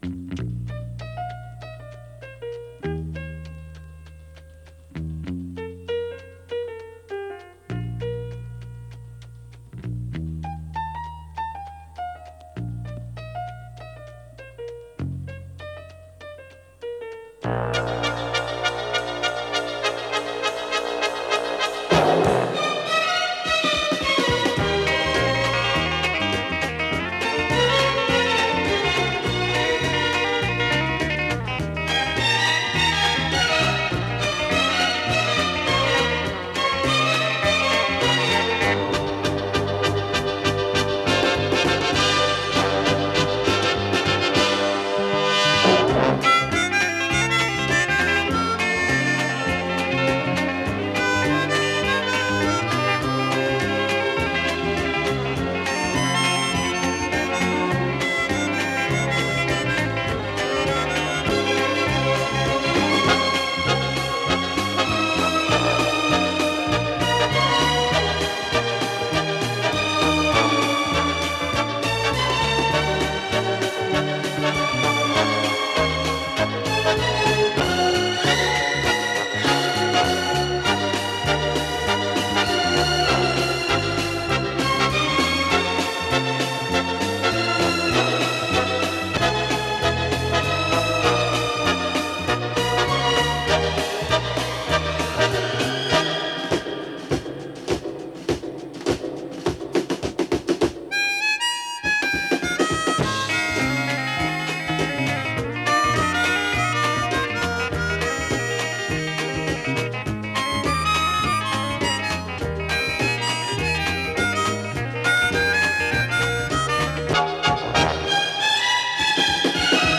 Скрипки всегда были главными «певцами» его оркестра.